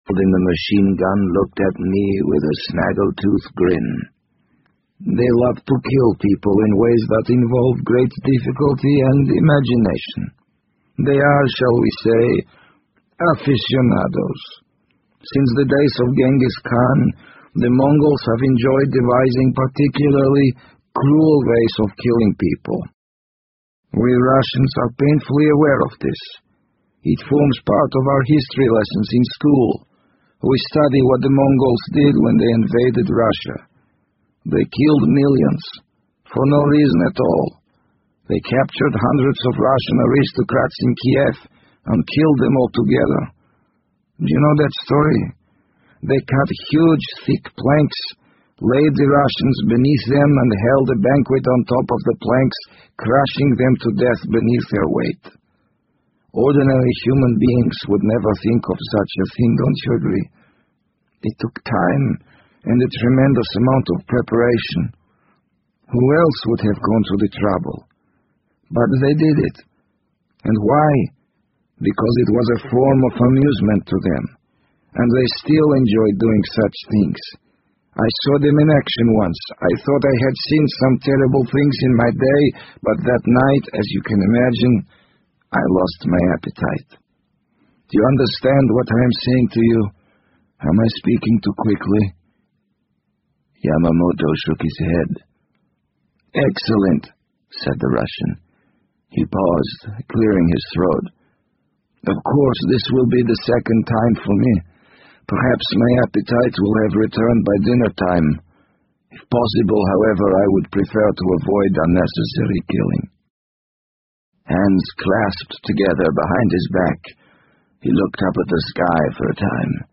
BBC英文广播剧在线听 The Wind Up Bird 004 - 17 听力文件下载—在线英语听力室